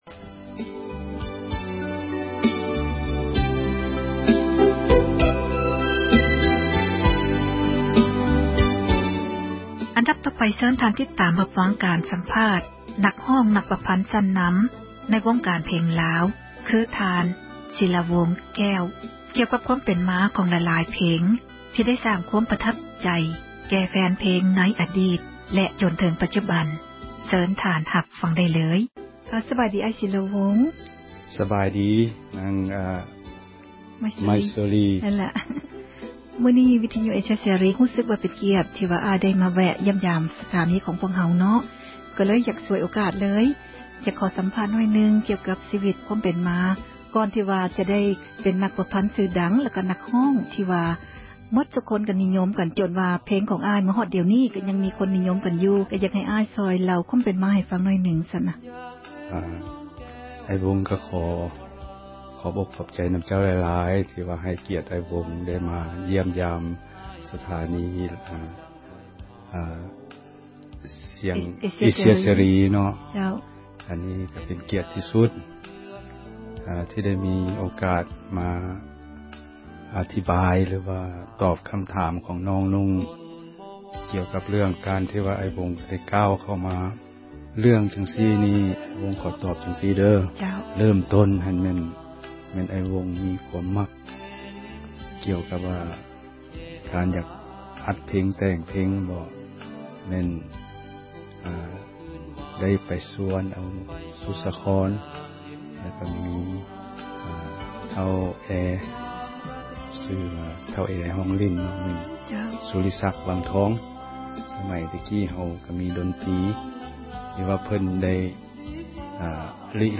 ສັມພາດນັກປະພັນ ນັກຮ້ອງອັມມະຕະ